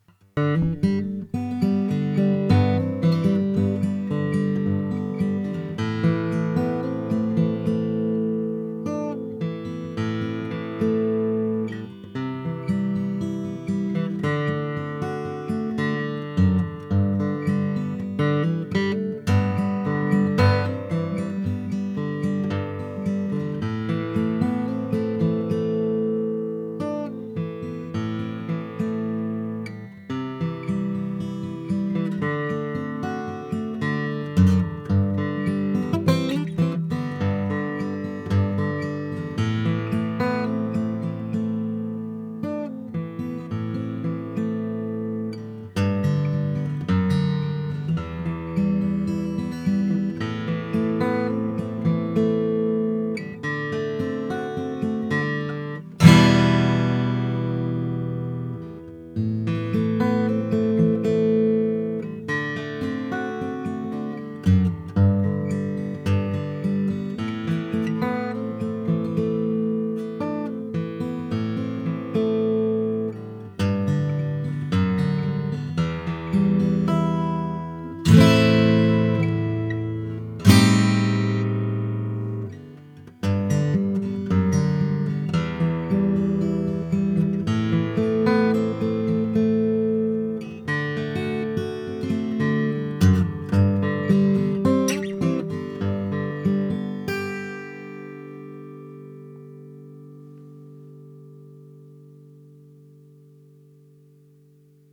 Body Shape Orchestra Model (OM)
Open, dynamic, and expressive — the Falcate OM delivers a slightly “bigger” sound than a traditional X-braced design.
• Richer overtones
• Longer sustain
• Improved bass response
• Sweet, articulate trebles